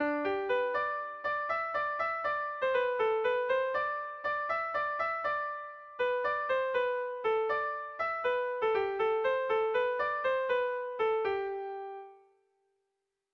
Erlijiozkoa
9A / 8B / 9A / 8B
AB